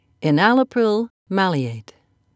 (e-nal'a-pril)
enalaprilmaleate.wav